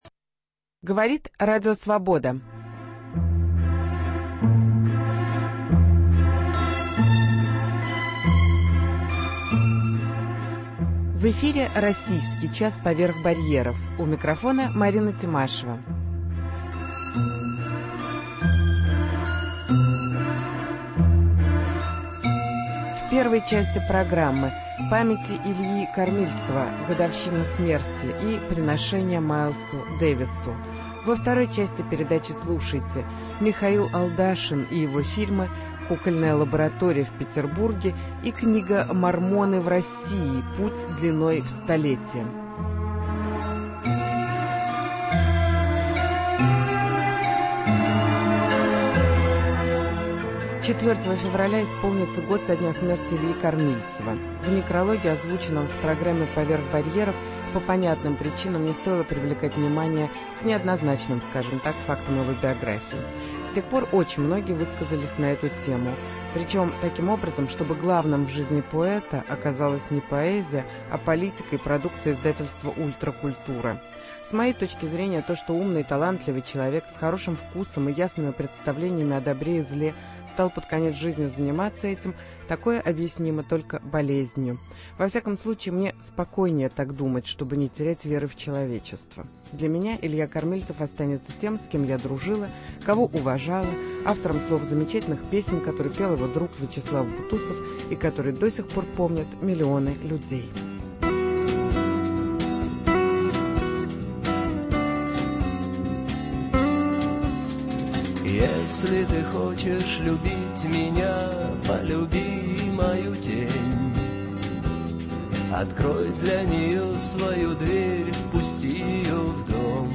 Памяти Ильи Кормильцева – вечер в театре "Практика"